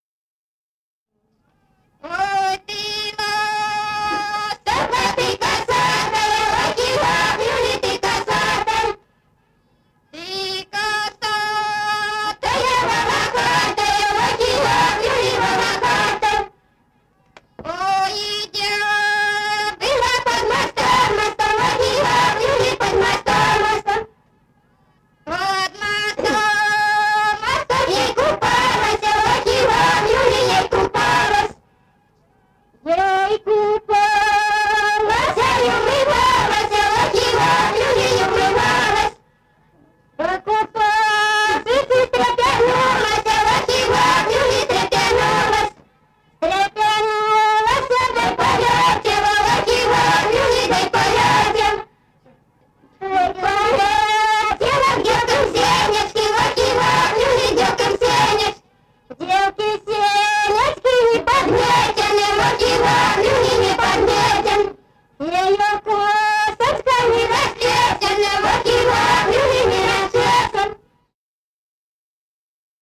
Народные песни Стародубского района «Ой, ты ластовка», юрьевские таночные.